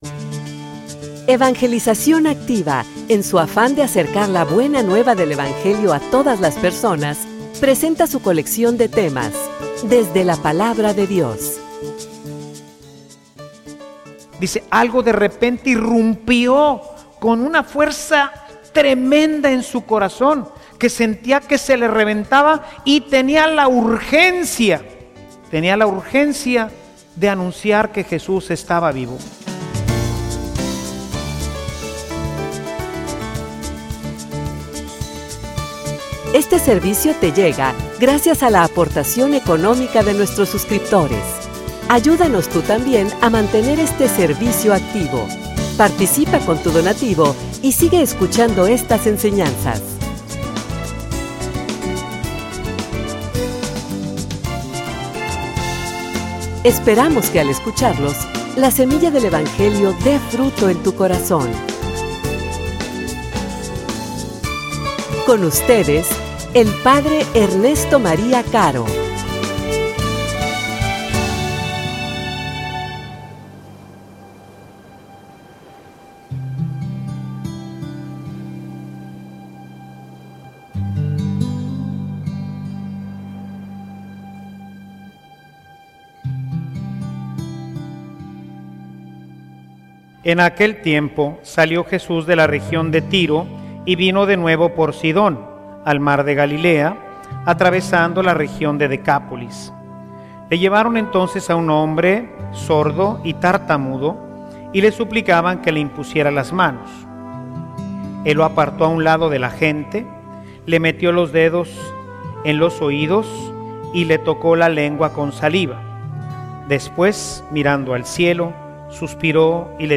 homilia_Sordos_y_mudos.mp3